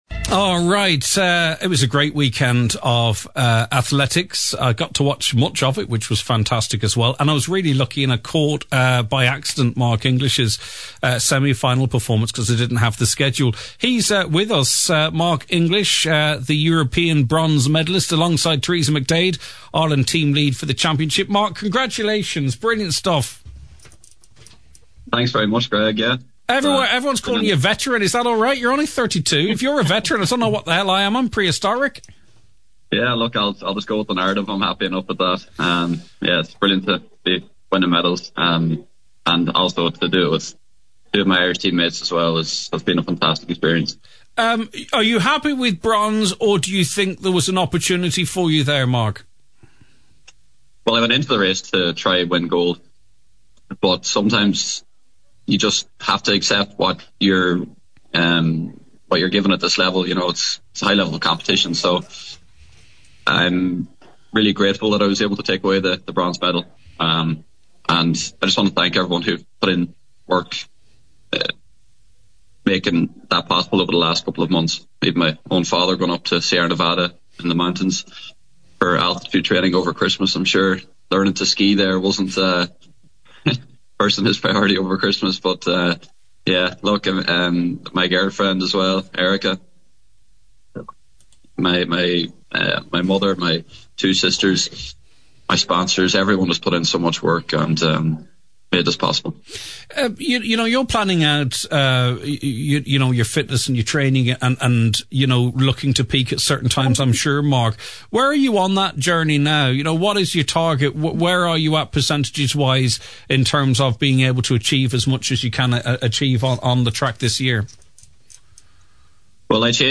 Mark-English-Interview.mp3